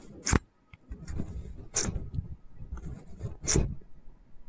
• phone tripod squealing.wav
Experience the unique, high-pitched sound of a phone tripod squealing. This sound effect captures the friction-induced noise produced when adjusting the position of a phone tripod.
phone_tripd_squealing_ZTV.wav